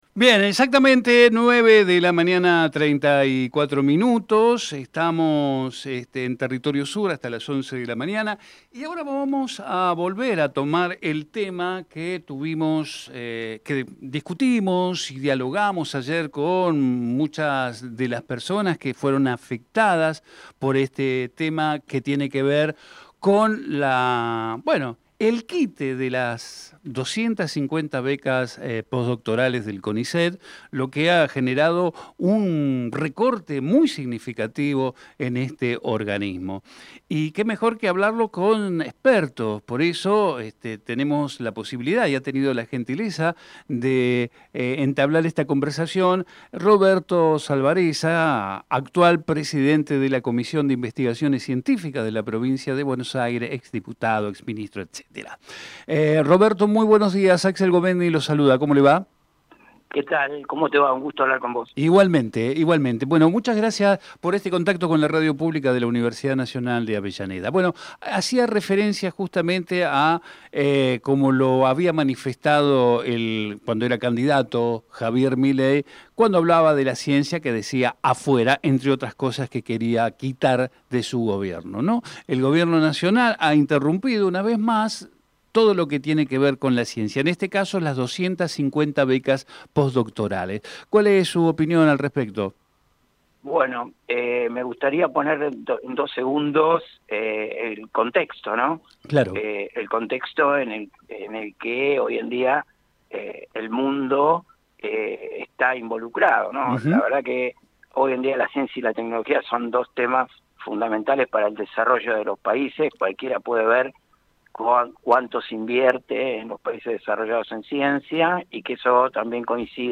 TERRITORIO SUR - ROBERTO SALVAREZZA Texto de la nota: Compartimos entrevista realizada en "Territorio Sur" a Roberto salvarezza- Presidente de la Comisión de Investigaciones Cientificas de la Provincia de Buenos Aires Archivo de audio: TERRITORIO SUR - ROBERTO SALVAREZZA Programa: Territorio Sur